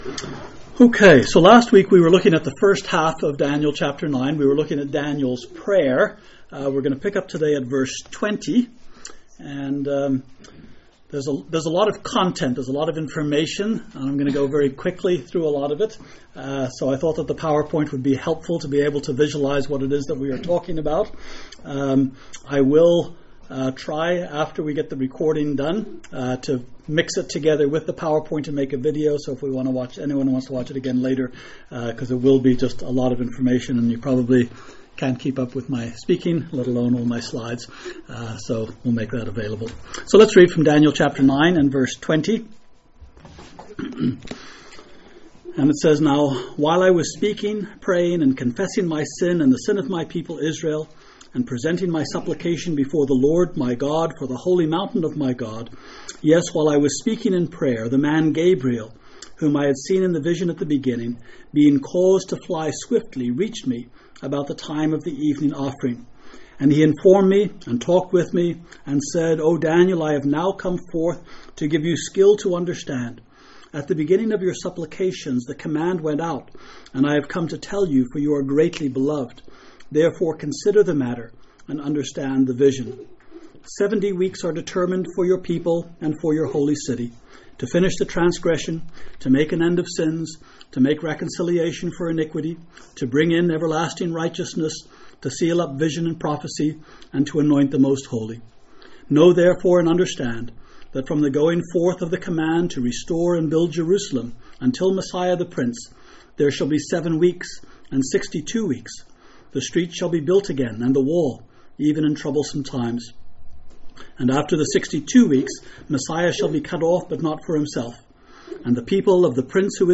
A message from the series "Daniel."